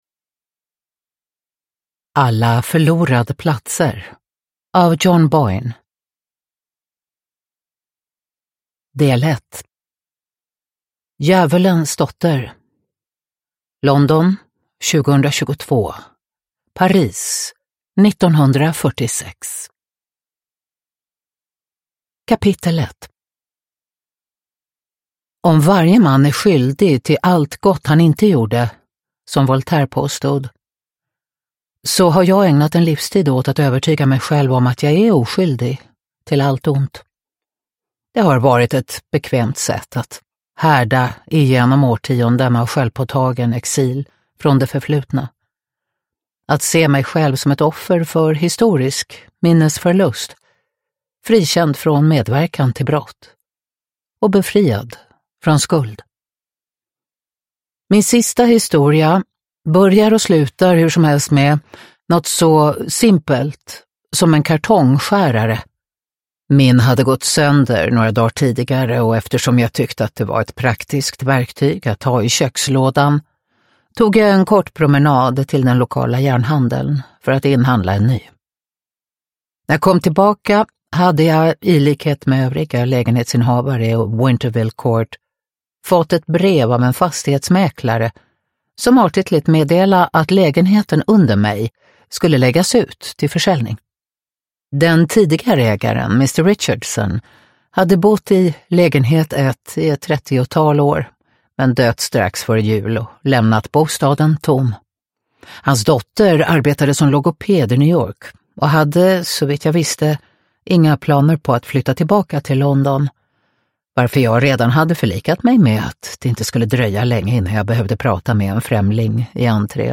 Alla förlorade platser – Ljudbok – Laddas ner
Uppläsare: Katarina Ewerlöf